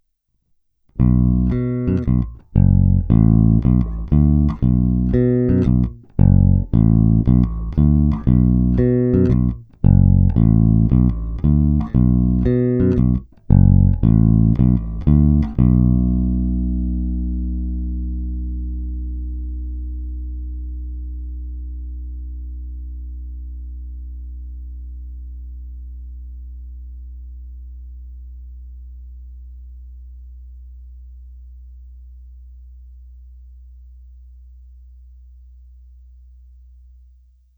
Zvuk je naprosto klasický Jazz Bass, zvláště v pasívním režimu.
Zvuk je obecně poměrně ostrý, agresívní, naštěstí není problém korekcemi výšek umravnit, nebo to pak lze samozřejmě řešit na aparátu.
Není-li uvedeno jinak, následující nahrávky jsou provedeny rovnou do zvukové karty, jen normalizovány, jinak ponechány bez úprav. Hráno vždy nad aktivním snímačem, v případě obou pak mezi nimi.
Oba snímače